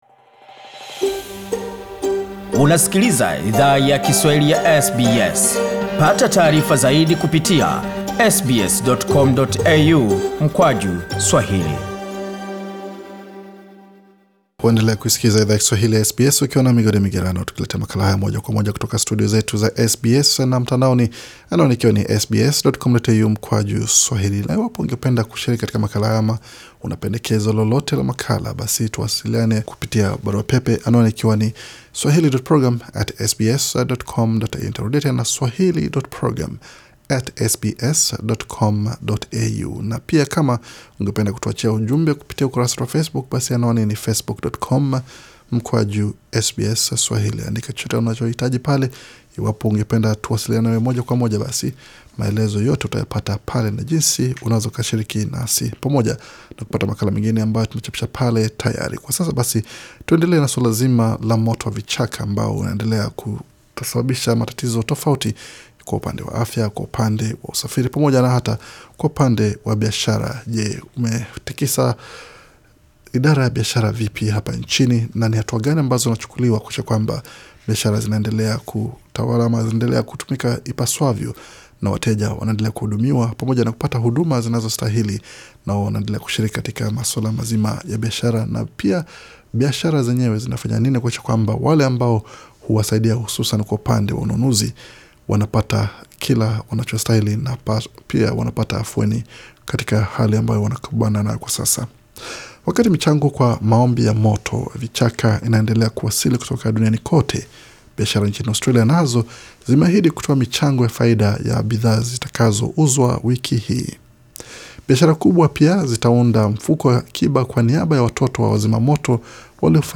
Wasanii maarufu watakao tumbuiza katika tamasha hiyo bado hawaja tangazwa, ila kuna uvumi kuwa msanii Queen ambaye sehemu ya wimbo wake umeusikio muda mfupi ulio pita atashiriki.